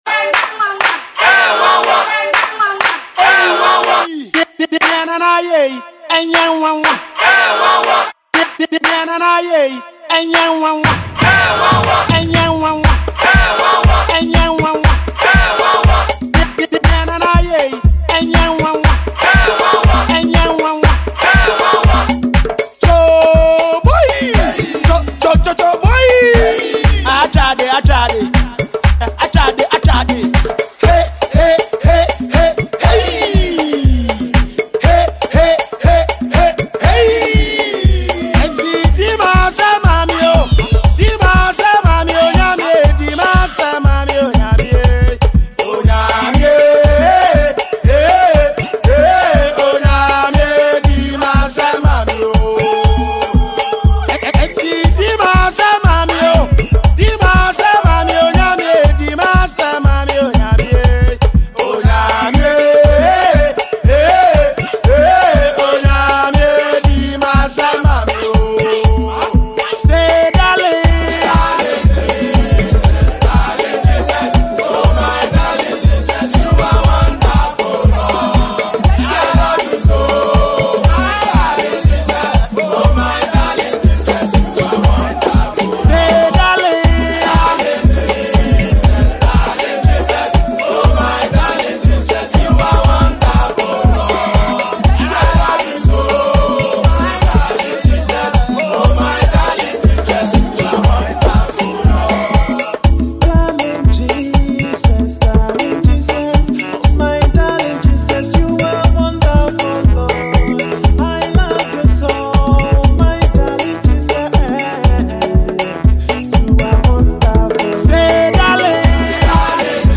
danceable song